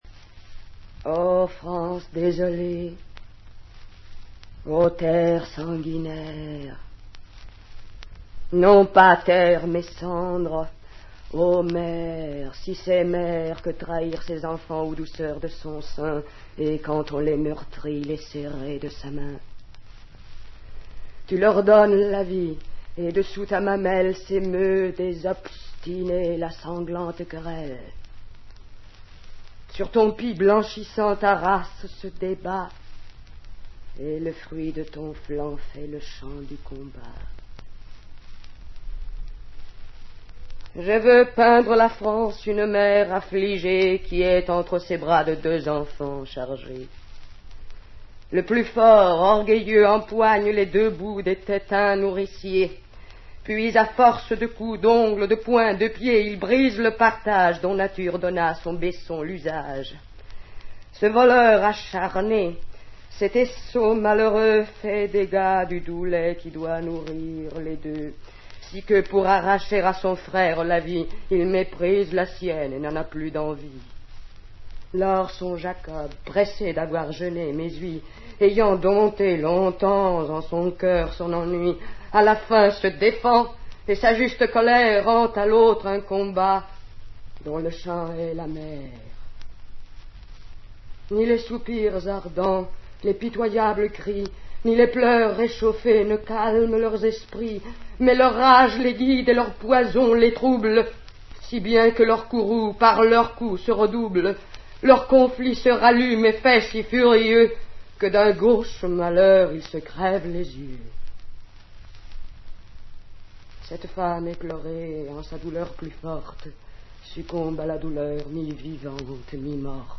dit par Maria CASARES